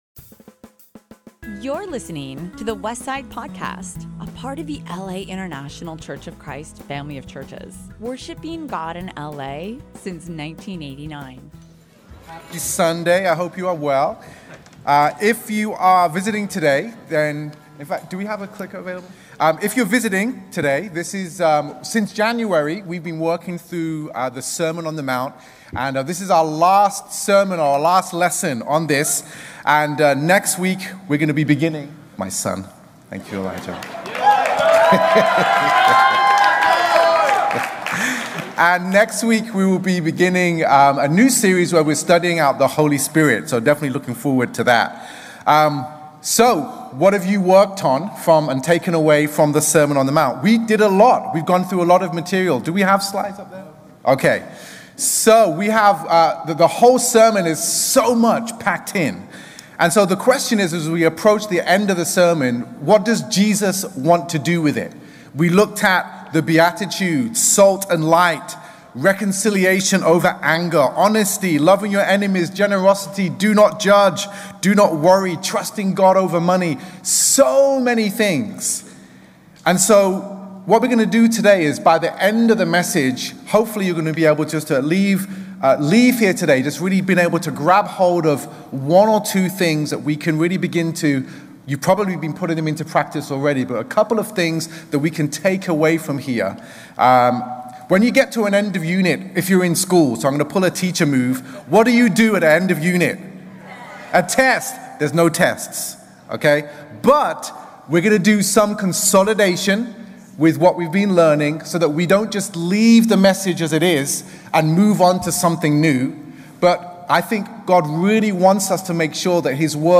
APPLICATION: Sermon On The Mount 1.